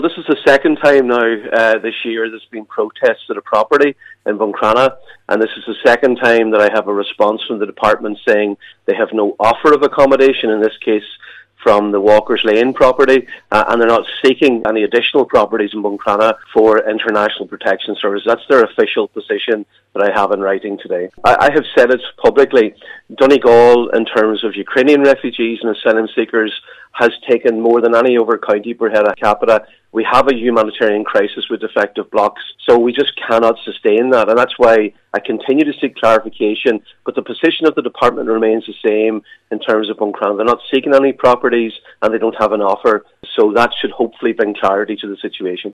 Deputy Mac Lochlainn says at this point, it would be unreasonable to expect Buncrana and Donegal to take any more…………….